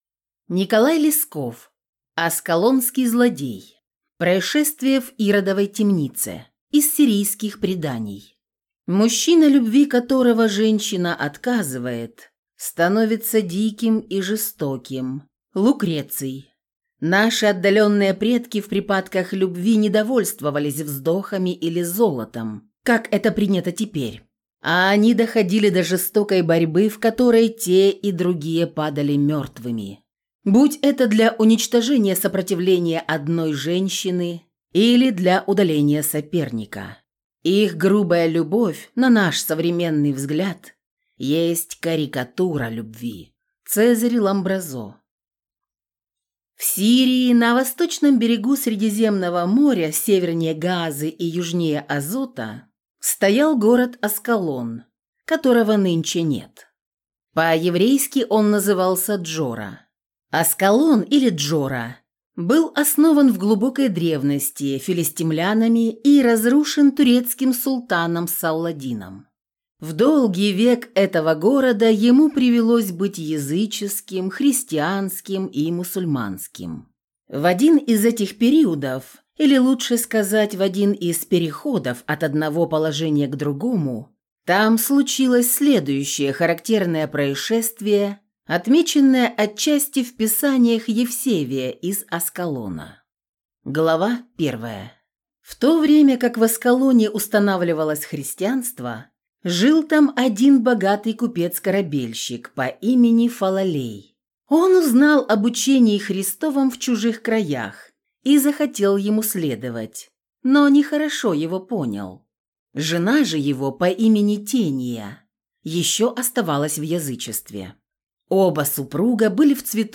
Аудиокнига Аскалонский злодей | Библиотека аудиокниг